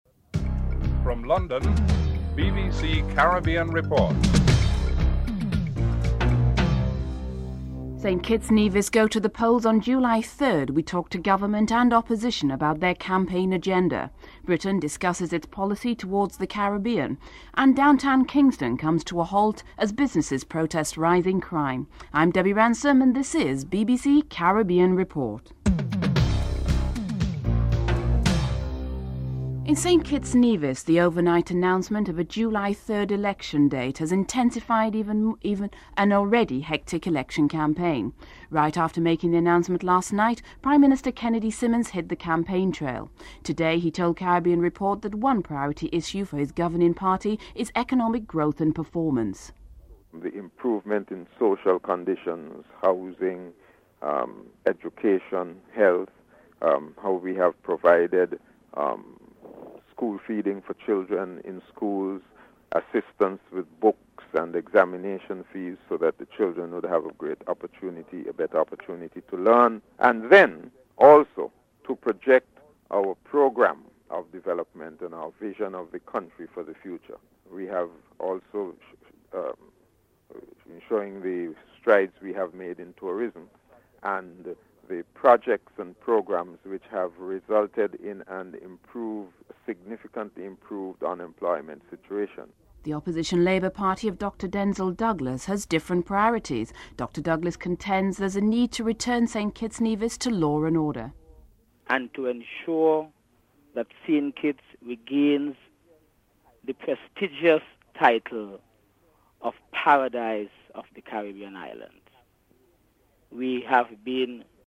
This report highlights the impending elections in St. Kitts/Nevis on July 3rd. Prime Minister Kennedy Simmonds and Opposition Labour Party, Denzil Douglas talk about their campaign agenda. Dominica's new Prime Minister Edison James finalizes his Cabinet and adopts the foreign affairs portfolio.
In the British Parliament, Sir Thomas Arnold comments on Britain's policy towards the Caribbean.